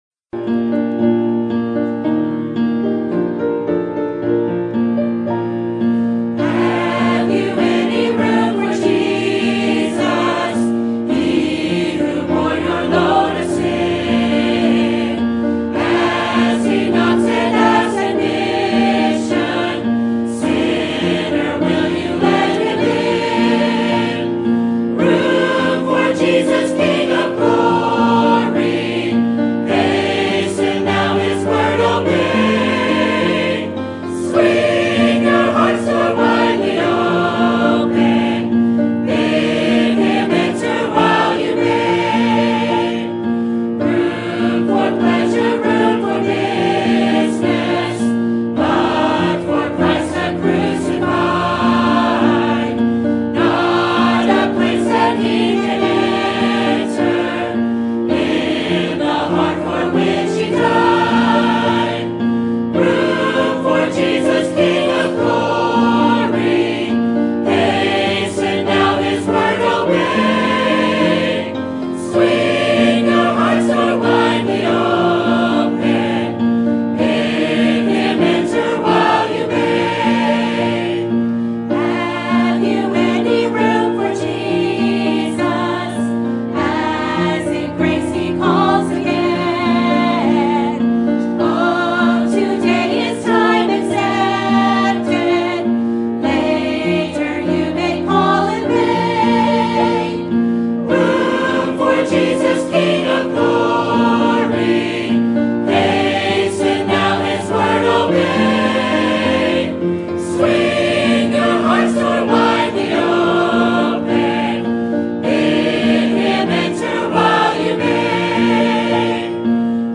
Sermons List | Calvary Baptist Church